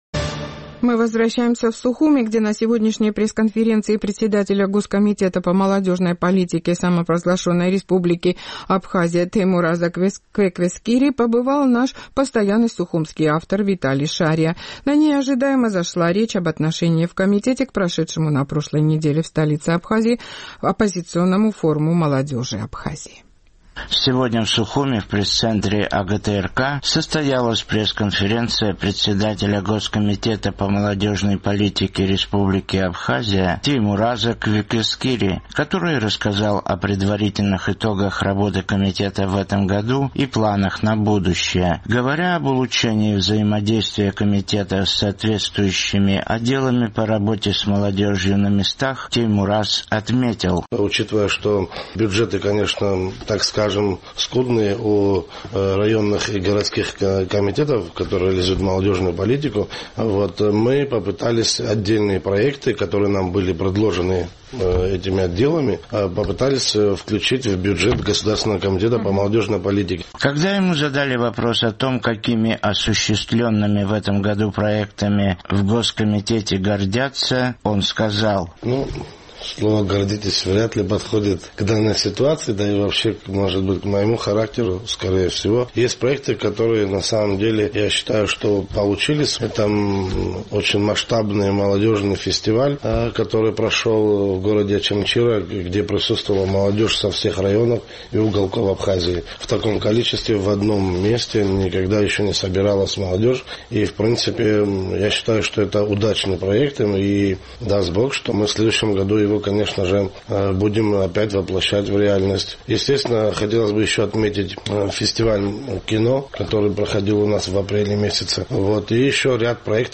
Сегодня в Сухуме в пресс-центре АГТРК состоялась пресс-конференция председателя Госкомитета по молодежной политике Республики Абхазия Теймураза Квеквескири, который рассказал о предварительных итогах работы комитета в этом году и планах на будущее.